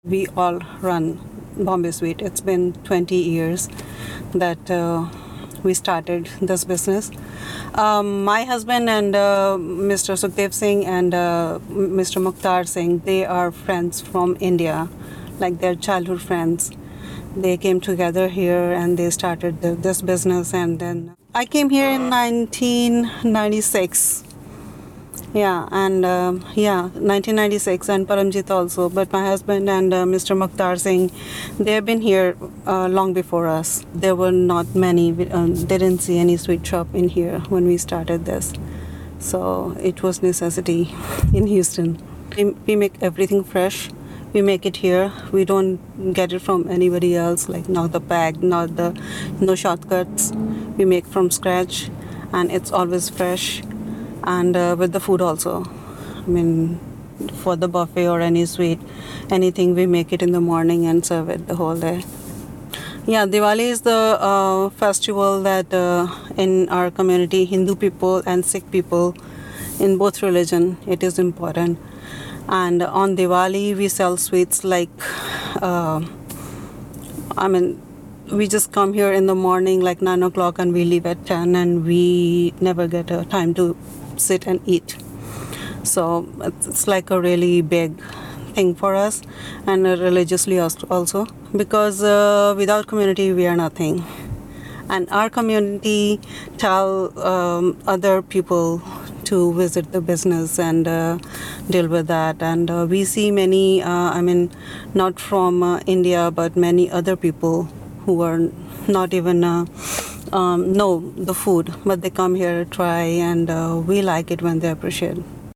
interview excerpt